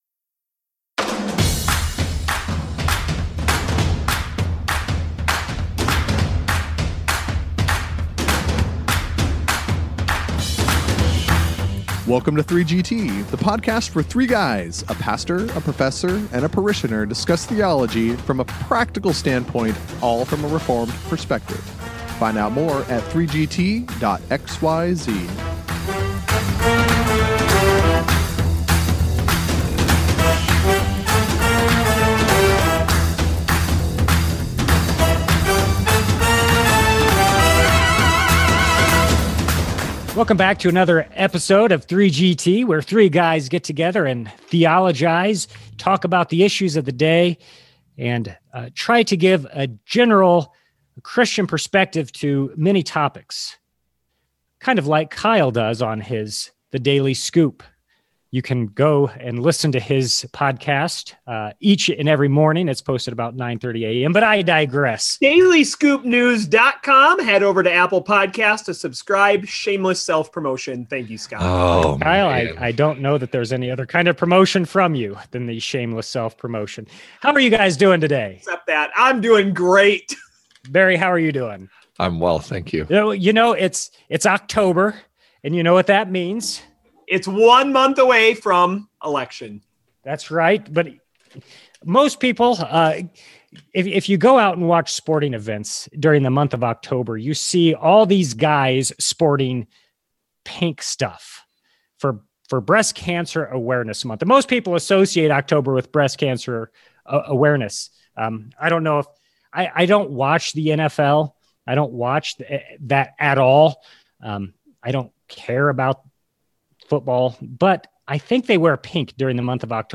But October is Pastor Appreciation Month. So he explores the subject of gratitude for pastors with the local pastor and the professor pastor.